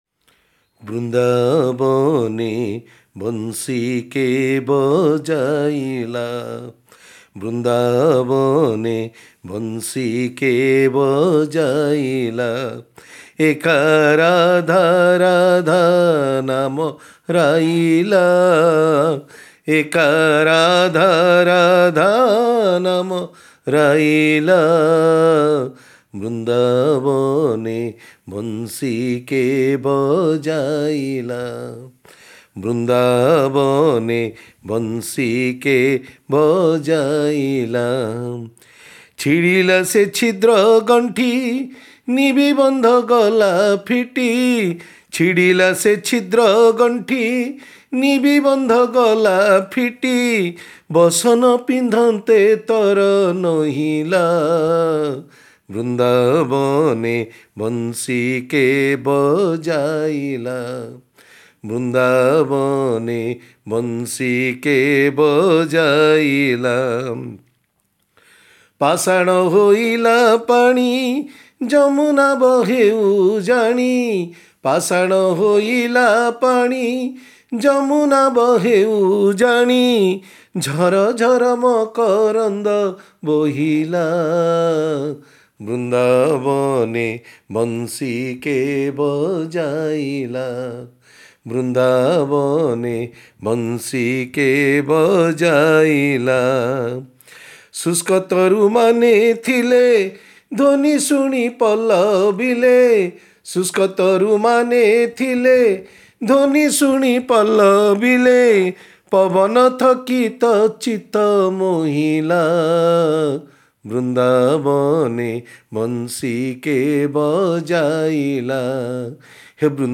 Playful Song (Sakhā Gīta) sung by Gopīs and Gopāḻas of Vṛndāvana glorifying the amazing happenings that take place when Kṛṣṇa plays the flute.